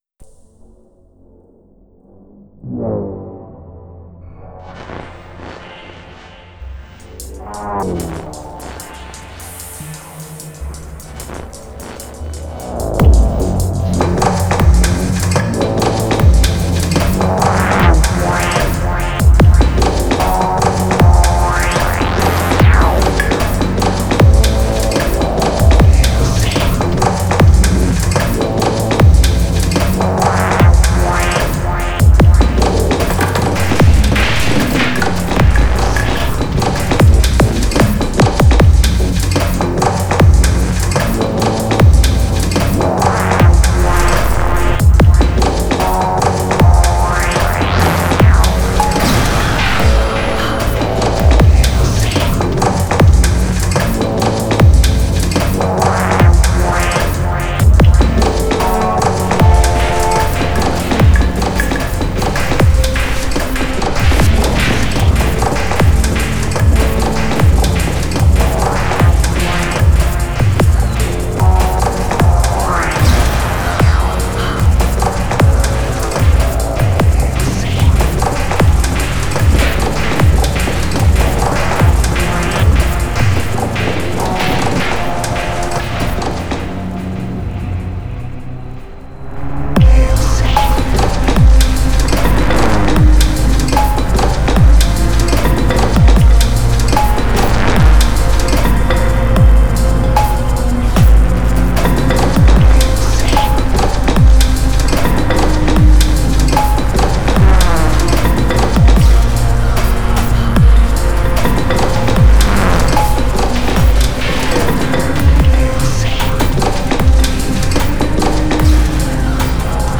Le jour se lève et c' est le presque week-end; un petit stomp dub.